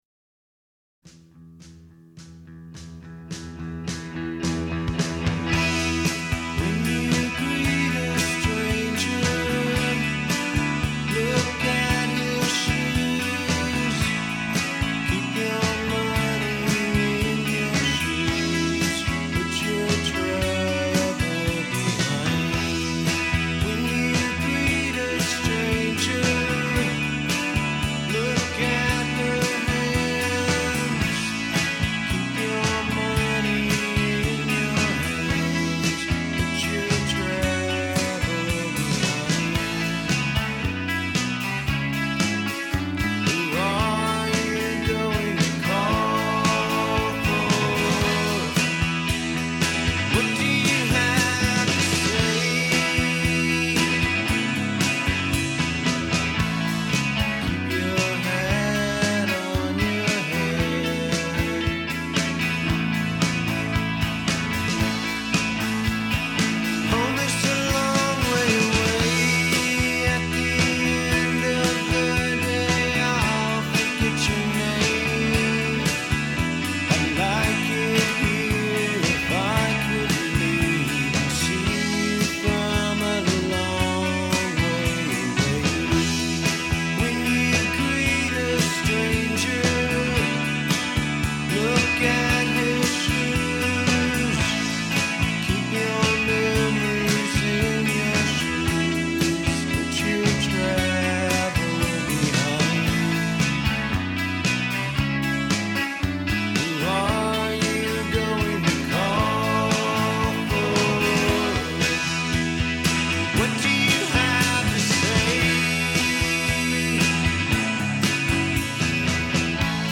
‘Folk Rock Derivative’